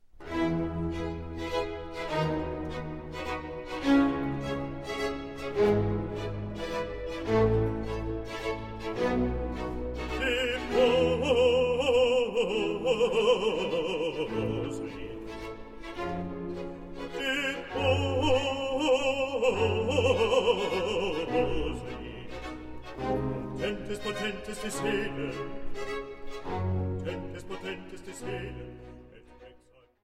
Live-Aufnahme ausd dem Hohen Dom zu Fulda